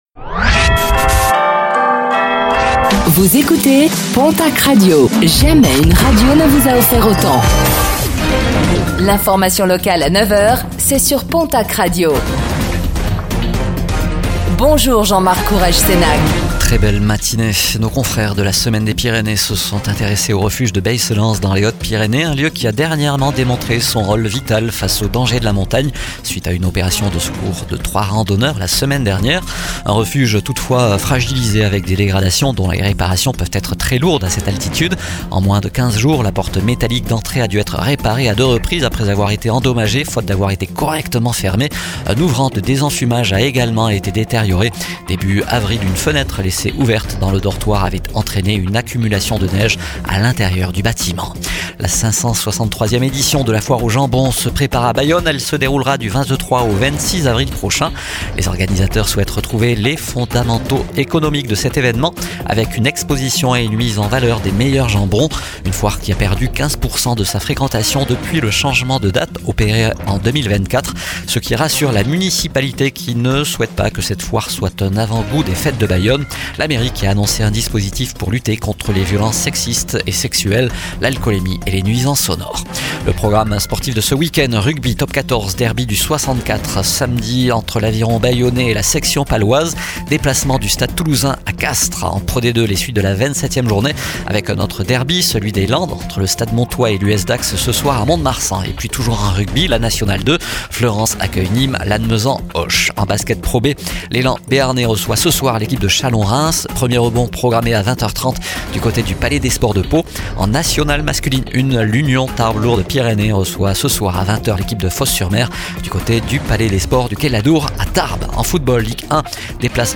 09:05 Écouter le podcast Télécharger le podcast Réécoutez le flash d'information locale de ce vendredi 17 avril 2026